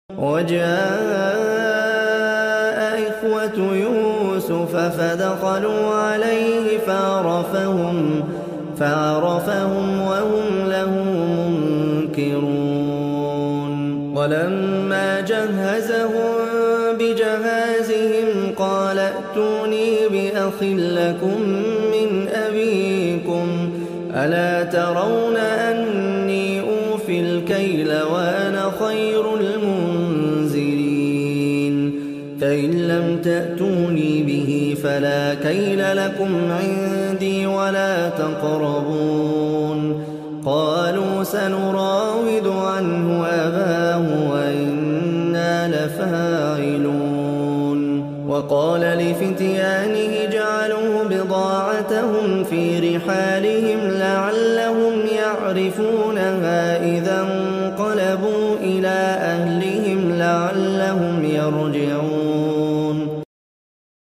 اروع التلاوات احسن تلاوة صوت عدب